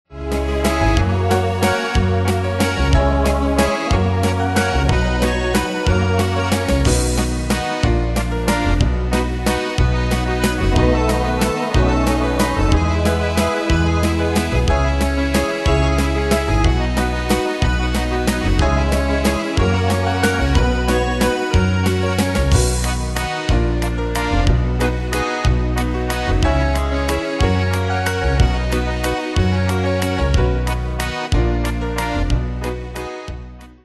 Style: Retro Ane/Year: 1962 Tempo: 183 Durée/Time: 2.54
Danse/Dance: Valse/Waltz Cat Id.
Pro Backing Tracks